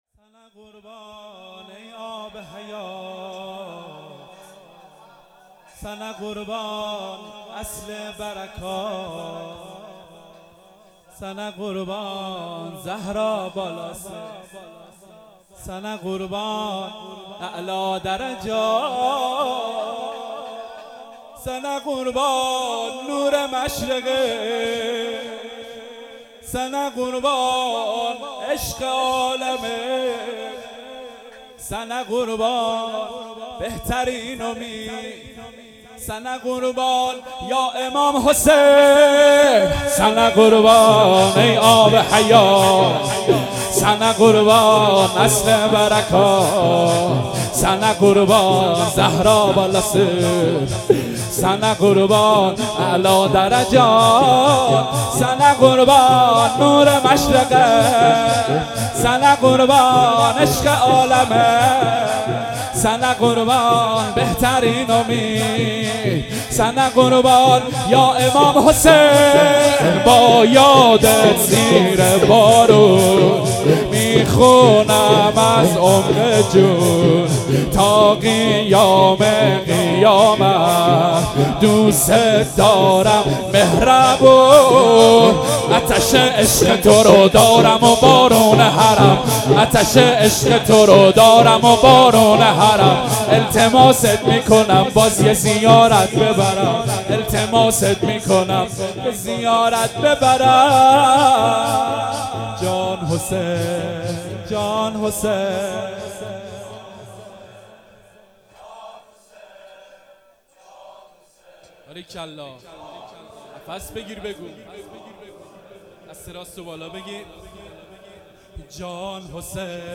مراسم شب ۲۸ محرم ۱۳۹۷